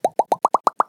boom.mp3